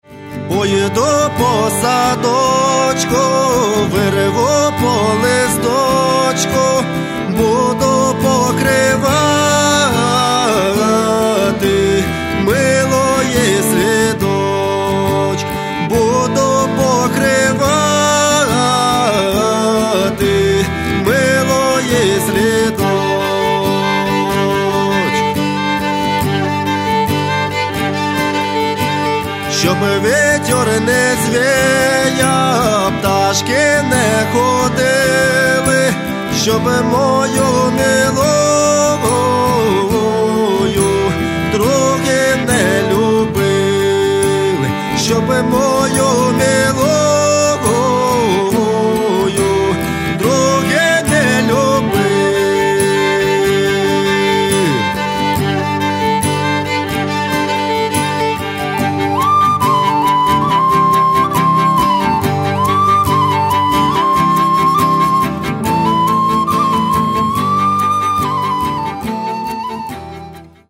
Каталог -> Народная -> Современные обработки